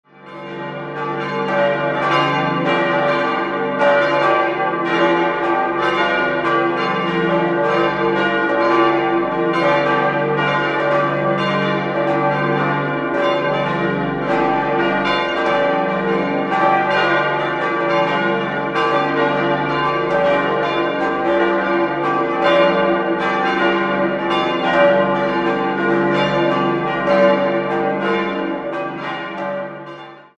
5-stimmiges ausgefülltes und erweitertes D-Moll-Geläute: d'-f'-g'-a'-c'' Die g'-Glocke wiegt 1.350 kg, hat einen Durchmesser von 115 cm und wurde 1730 von Neumaier (Stadtamhof) gegossen.